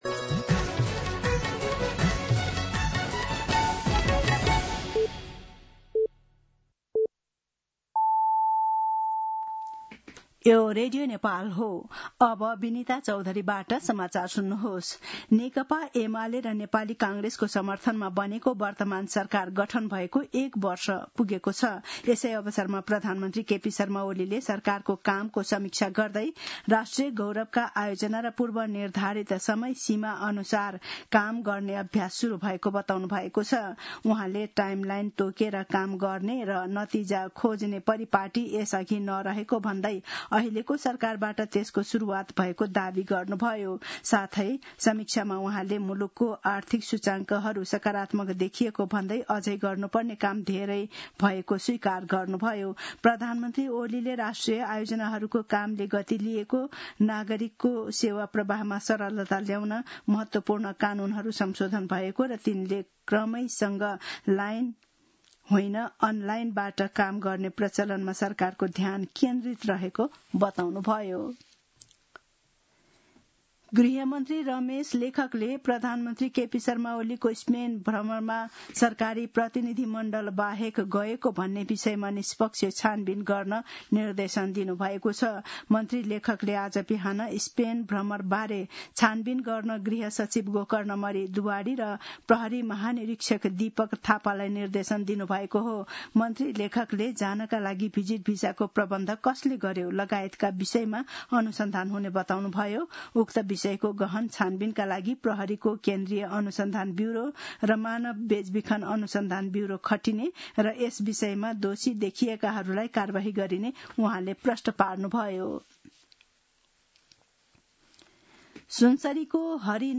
दिउँसो १ बजेको नेपाली समाचार : ३० असार , २०८२